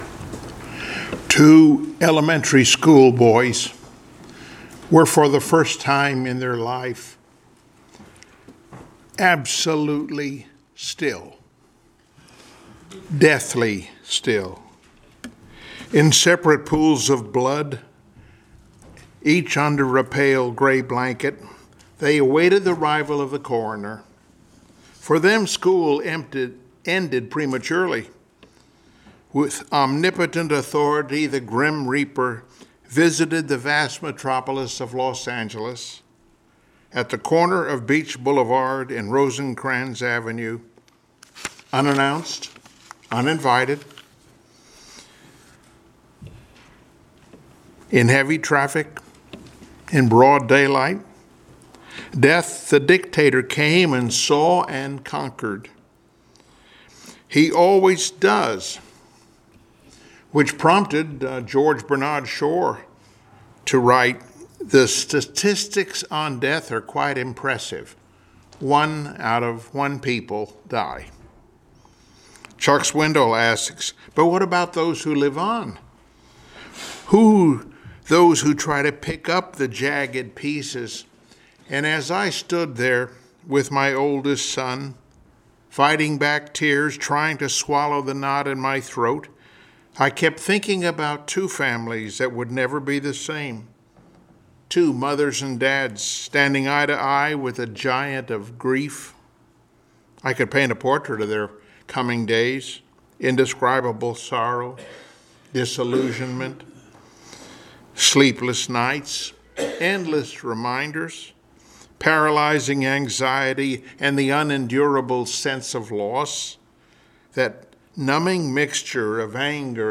Passage: John 11:1-44 Service Type: Sunday Morning Worship